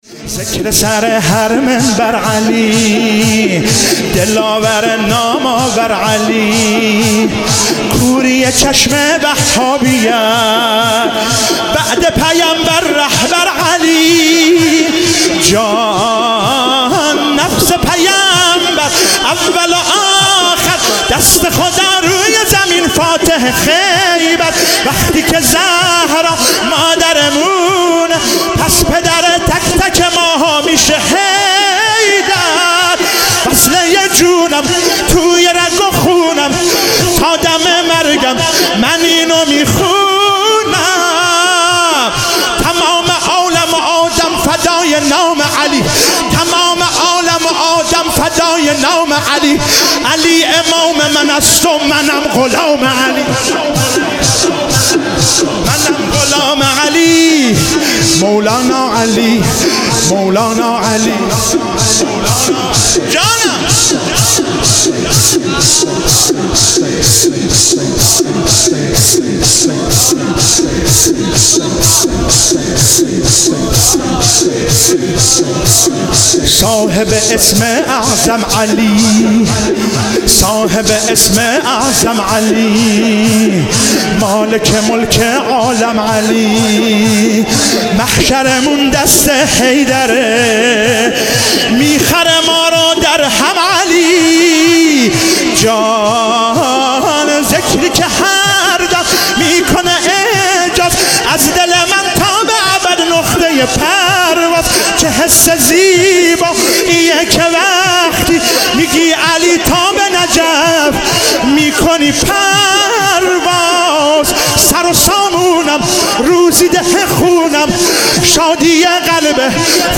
شب 19 رمضان97 - شور - ذکر سر هر منبر علی